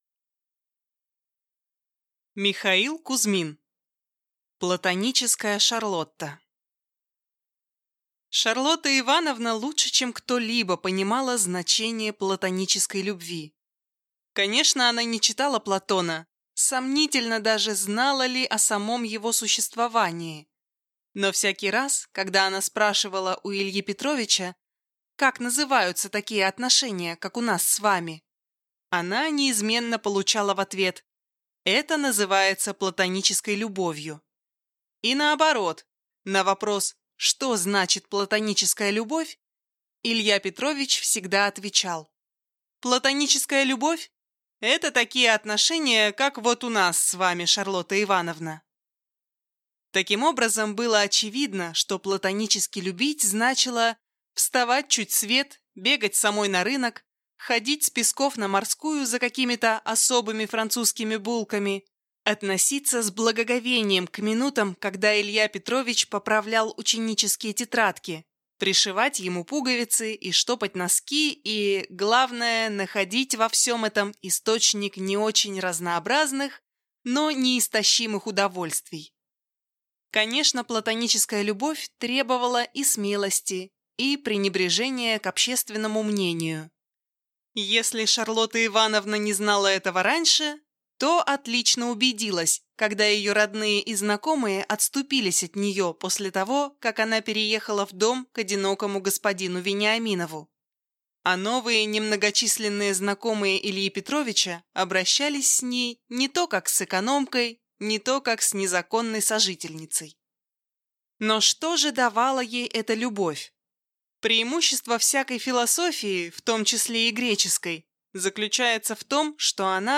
Аудиокнига Платоническая Шарлотта | Библиотека аудиокниг